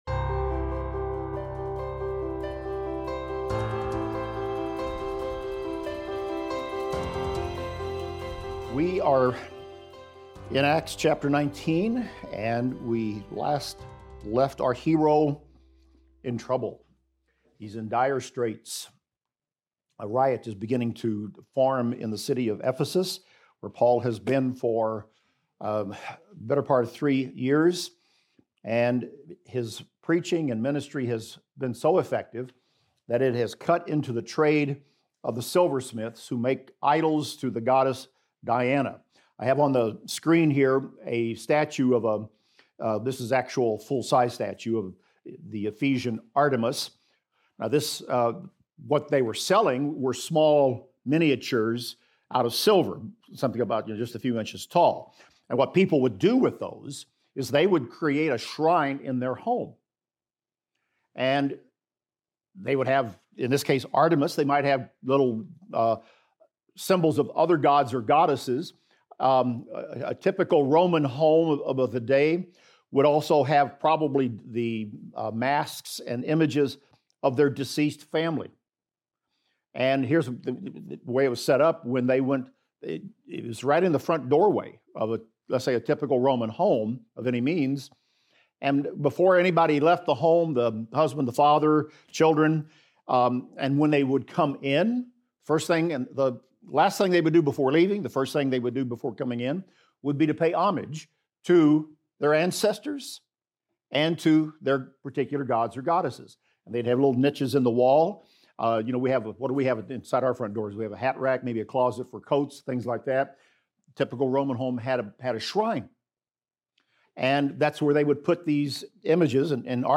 In this class we will discuss Acts 19:25-41 thru Acts 20:1-3 and examine the following: Demetrius, the silversmith, stirs up a riot among fellow craftsmen in Ephesus because Paul's preaching threatens their idol-making trade.